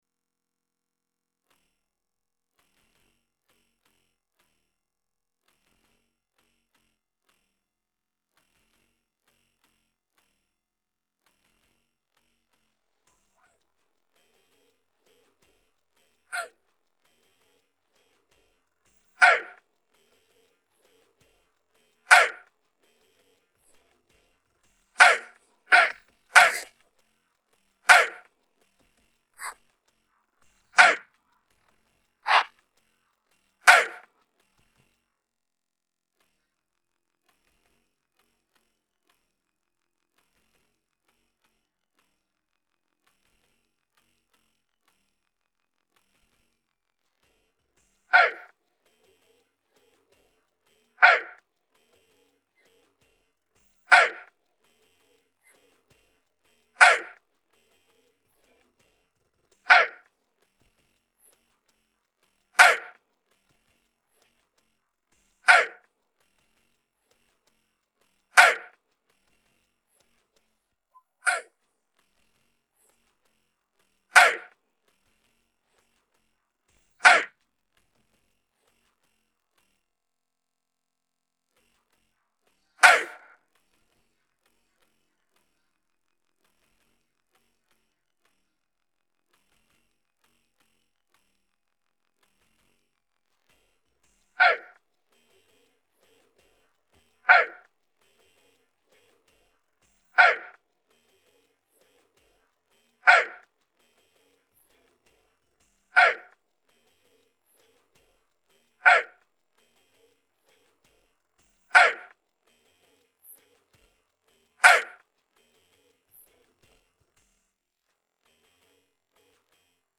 Parte vocale